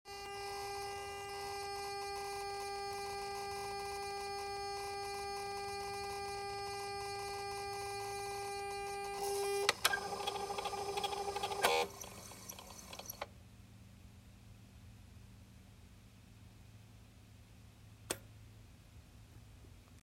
I've noticed the throttle body is noisy after I turn the car off.
It hums from the time I turn the car off, until it goes through what appears to be a homing cycle. After all this, a couple seconds later I hear a "puff" in the area that I'm guesing is fuel pressure or vaccum relief through a solenoid in the area.